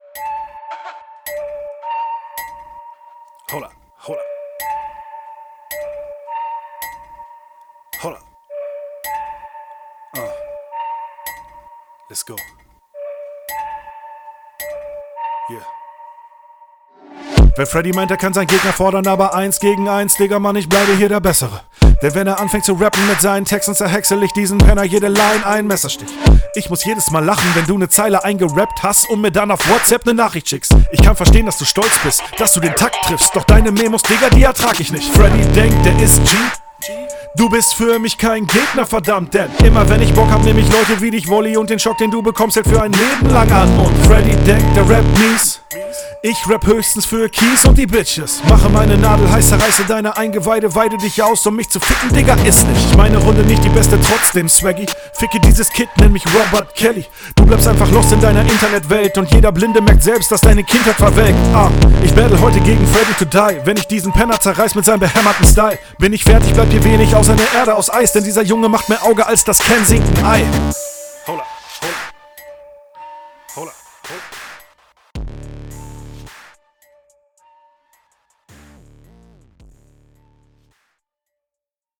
Oh, Flow ist echt geil auf dem Beat. Textlich und Stimmlich auch geil!.